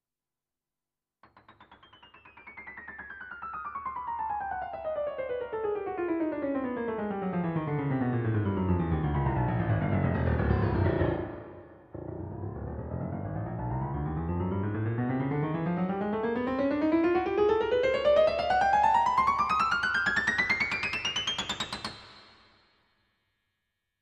La prima prova che faccio per testare uno strumento è una serie di scale cromatiche, ascendenti e discendenti, che attraversino tutta la tastiera in regolare crescendo.
Grand Piano 1 - scale cromatiche
AA-GrandPiano1.mp3